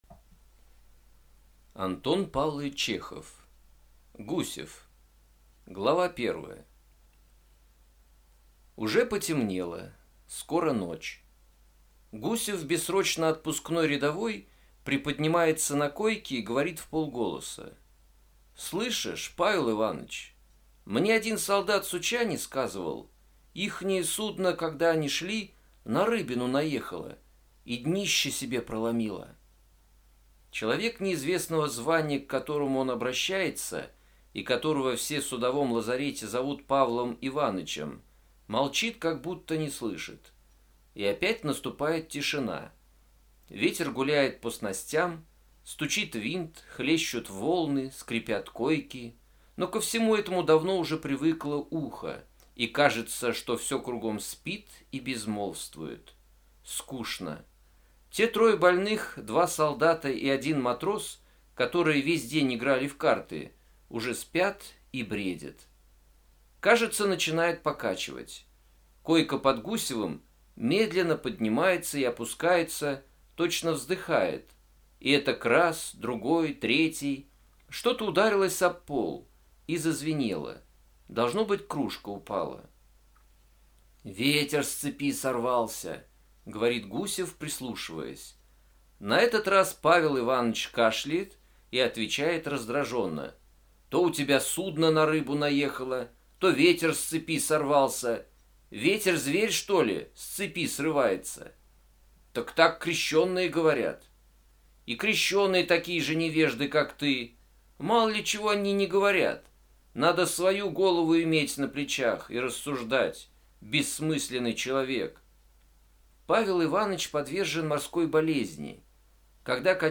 Аудиокнига Гусев | Библиотека аудиокниг